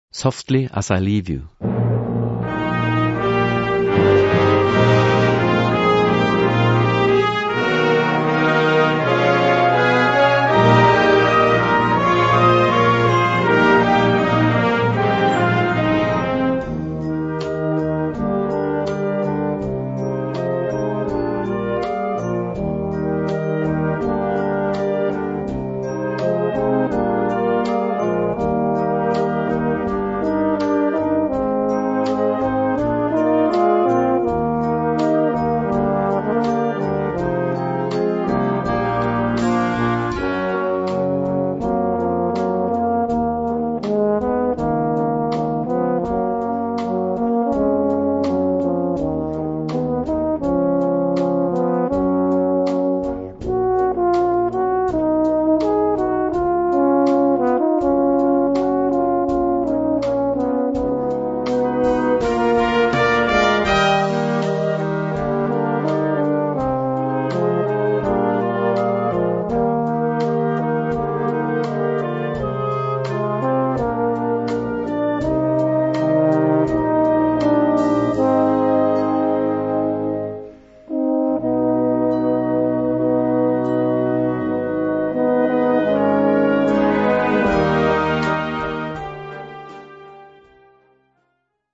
Solo für zwei Euphonium und Blasorchester
Besetzung: Blasorchester